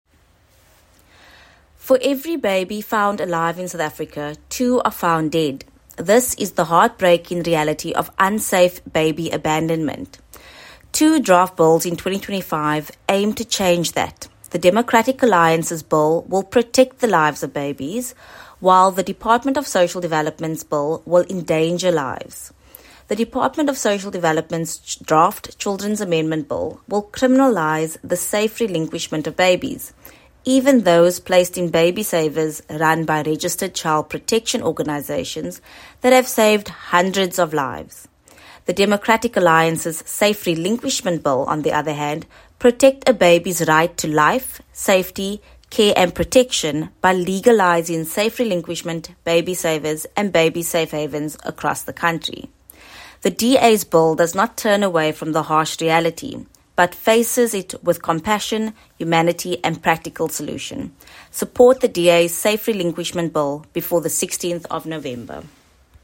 Soundbite by Alexandra Abrahams MP.